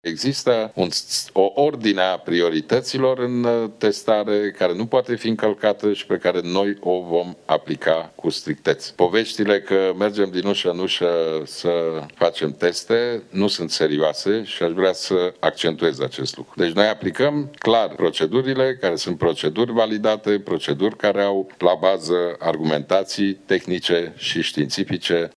Premierul Ludovic Orban, la începutul ședinței de Guvern: